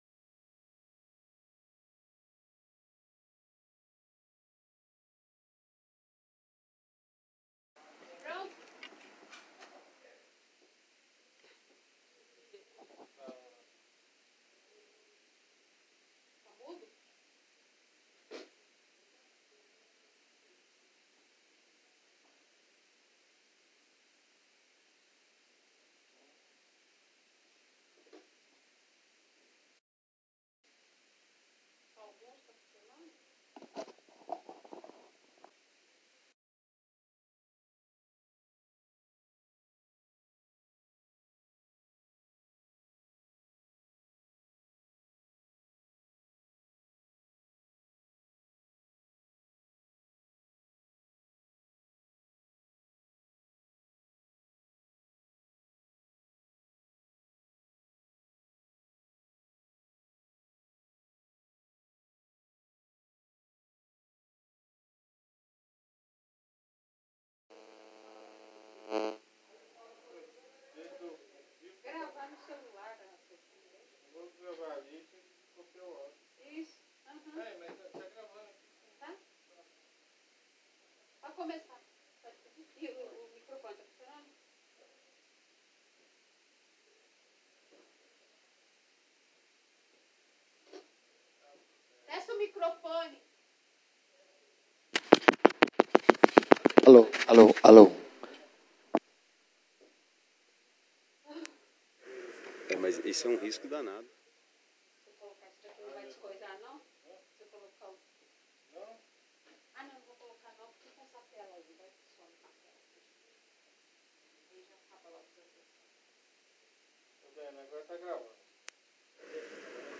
Áudio da Sessão Ordinária realizada no dia 30/10/2017 as 20 horas no Plenário Henrique Simionatto.